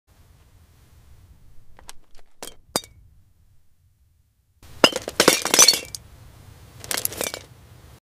ASMR glass garden fruit, grapes sound effects free download